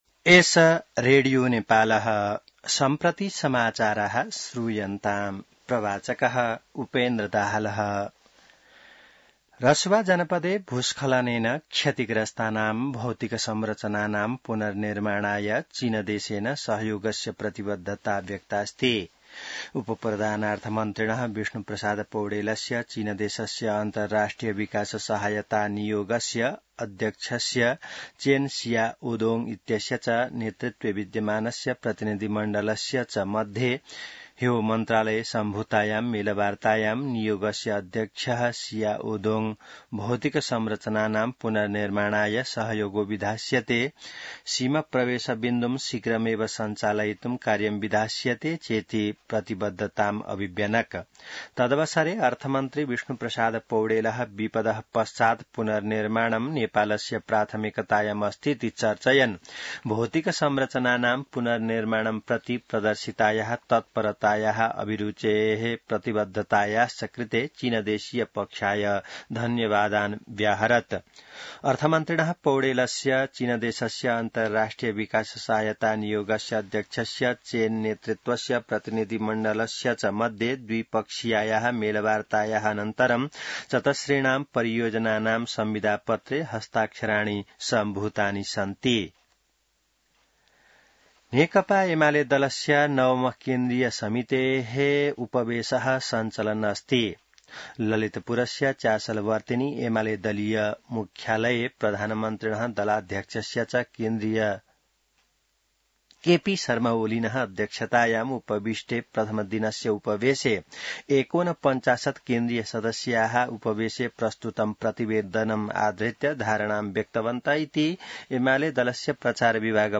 An online outlet of Nepal's national radio broadcaster
संस्कृत समाचार : ६ साउन , २०८२